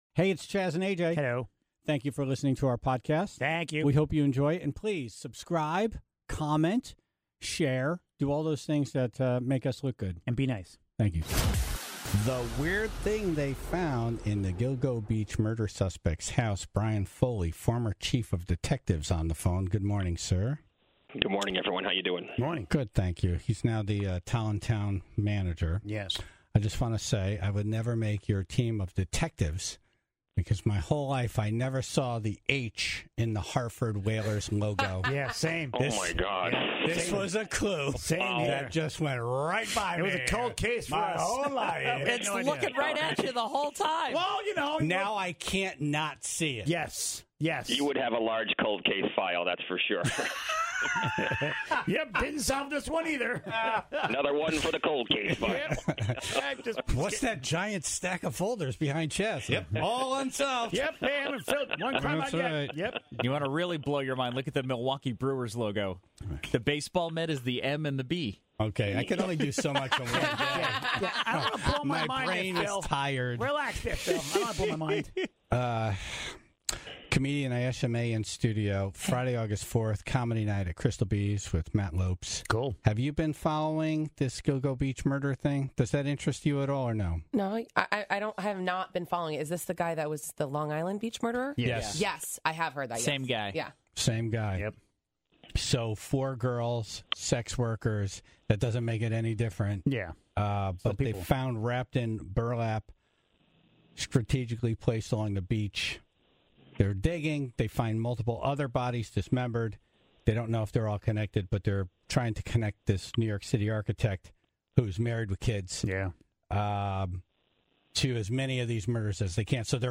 (13:59) Comedian Adam Carolla was live from California.